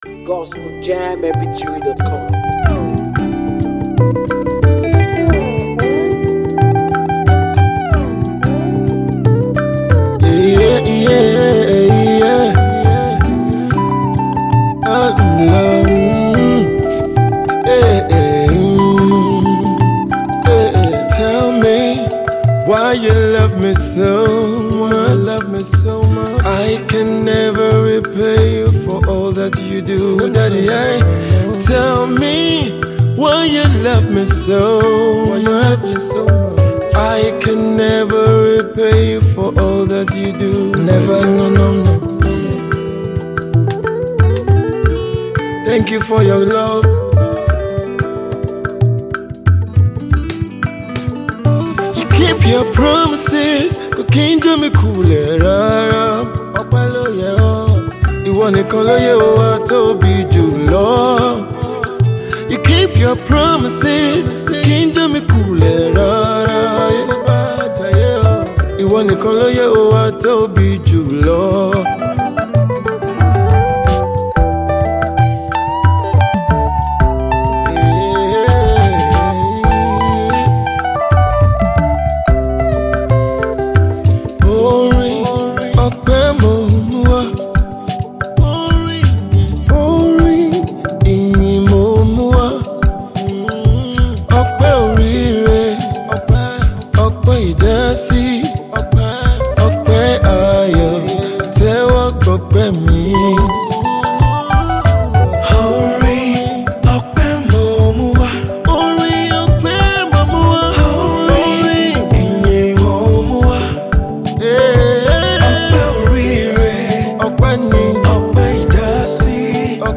gospel mixtape
Anointed worship songs ✔ Spirit-lifting praise vibes